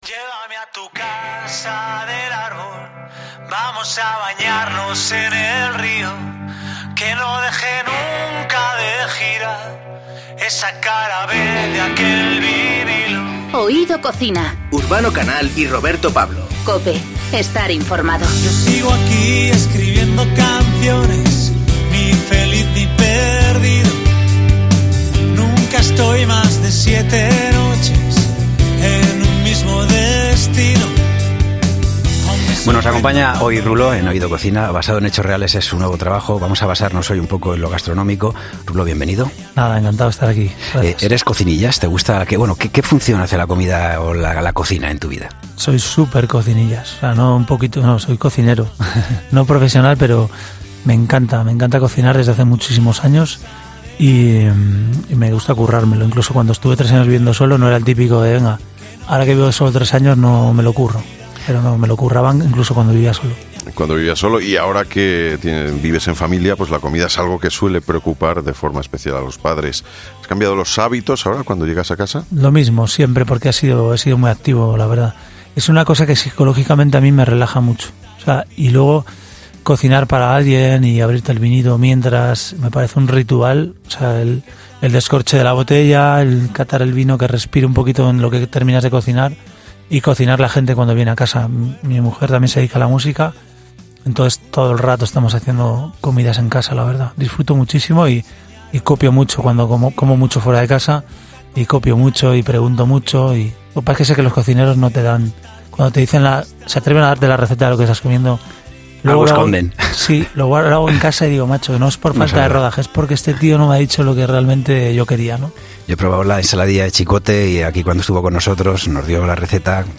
Un tipo que nos ha sorprendido con sus conocimientos y gustos gastronómicos. Una charla de lo más apetecible y tan afinada como sus canciones.